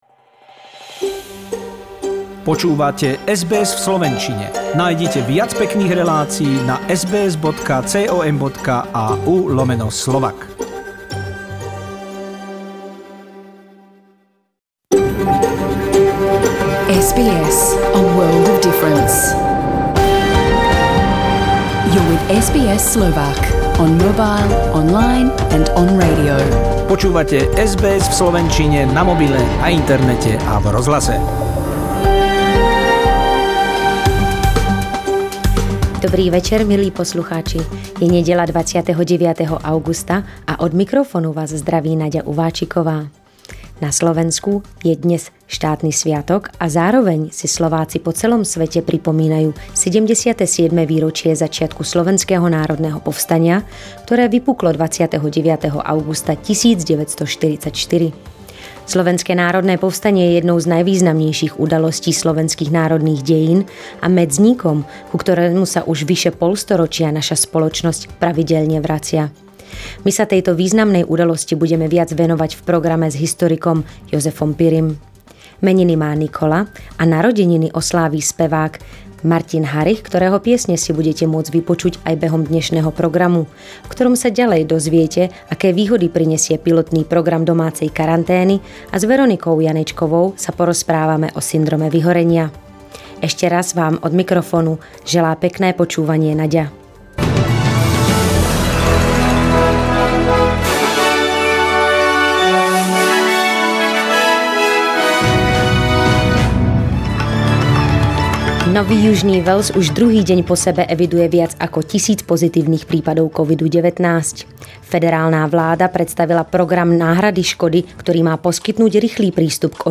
Dnešné správy v slovenčine 29.augusta 2021